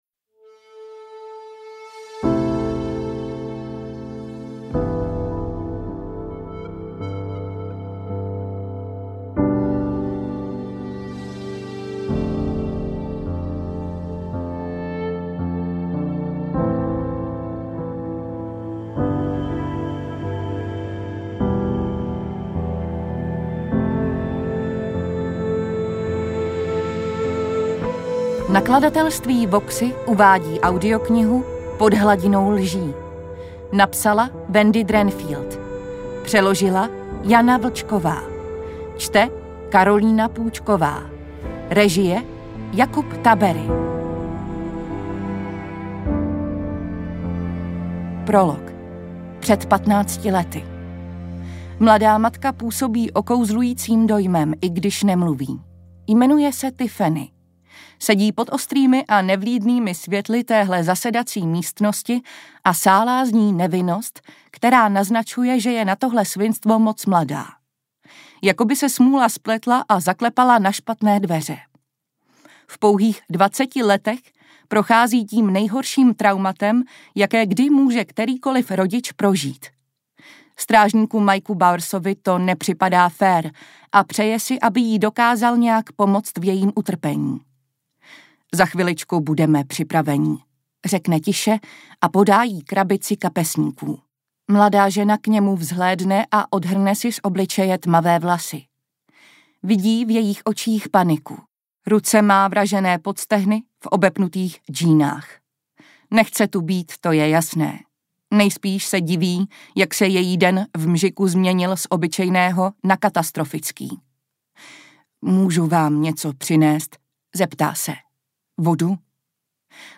AudioKniha ke stažení, 67 x mp3, délka 12 hod. 56 min., velikost 706,0 MB, česky